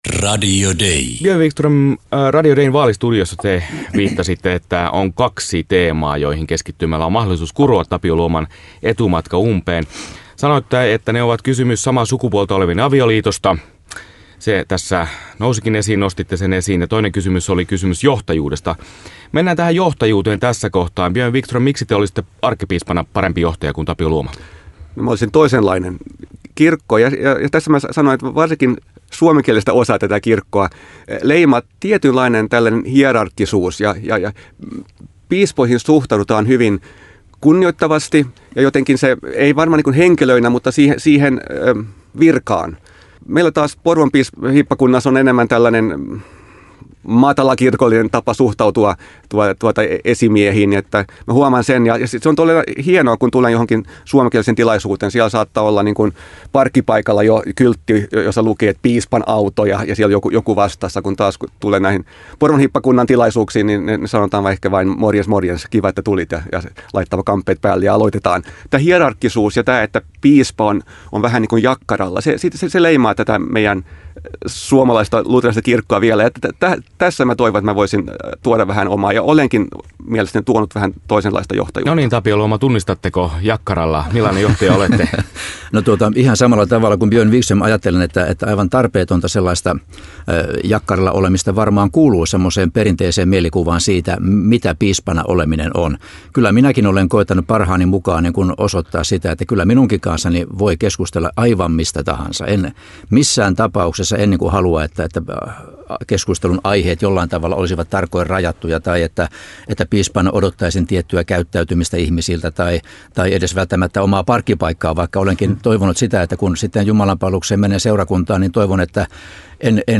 Johtajuuden puute ja kysymys samaa sukupuolta olevien parien avioliitosta olivat terävimmät kärjet, joilla Björn Vikström haastoi Tapio Luoman kaksikon kohdatessa Radio Dein arkkipiispatentissä. Lähetyksessä sanailtiin myös piispoille nimikoiduista parkkipaikoista.